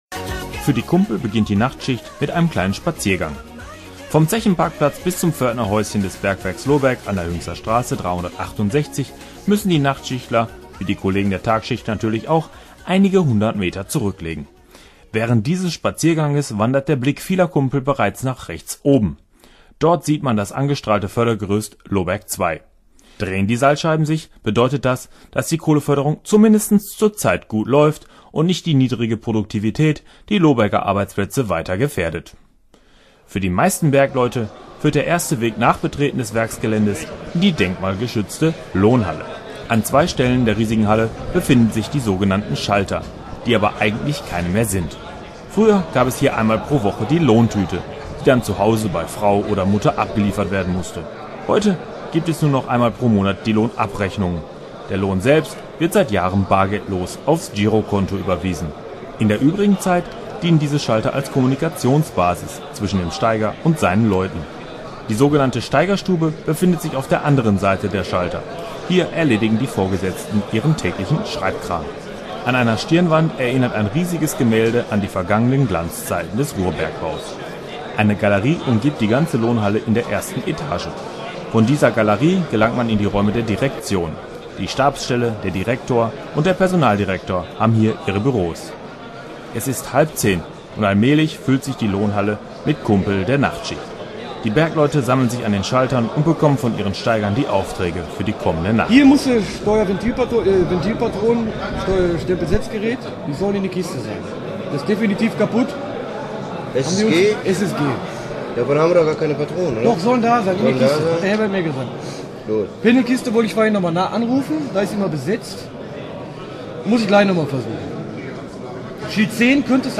Im März 2002, also noch bevor die Stilllegung des Bergwerks Lohberg/Osterfeld beschlossen war, produzierte die Radiowerkstatt der Volkshochschule Dinslaken-Voerde-Hünxe ein Radio-Feature über eine ganz normale Nachtschicht auf dem Bergwerk.
Die Musik musste aus urheberrechtlichen Gründen entfernt werden (da versteht die Plattenindustrie keinen Spass).
Hier wird Lohberg nochmal lebendig: Hektik in der Lohnhalle, emsiges Treiben in der Kaue, lautes Dröhnen in der Kohlenwäsche, das Gequäke der Beschallungsanlagen und auch die Schachtglocke lädt nochmal zu einer Grubenfahrt.